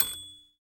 Index of /90_sSampleCDs/Roland - Rhythm Section/PRC_FX Perc 1/PRC_Typewriter
PRC TYPBELL.wav